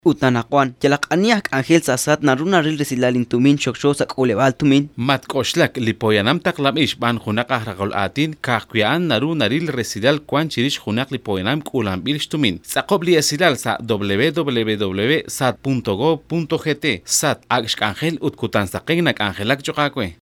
• Spot de radio idiomas mayas.